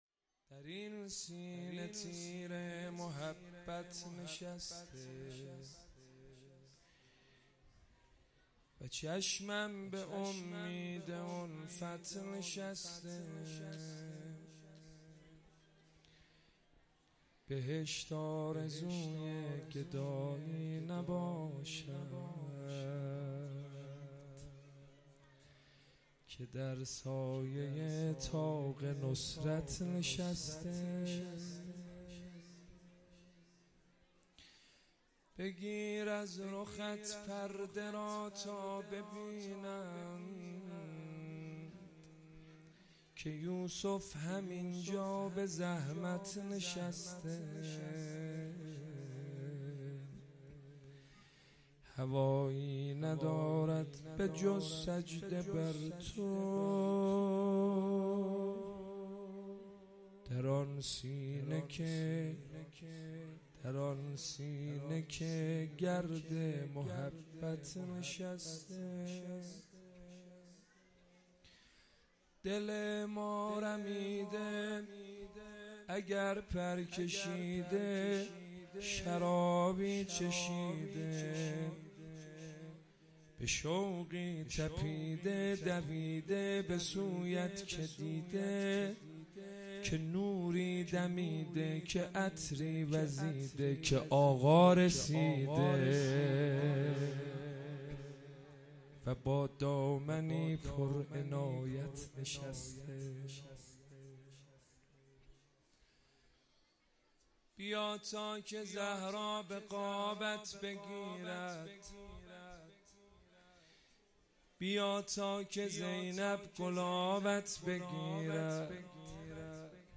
جشن ولادت امام زمان نیمه شعبان 1446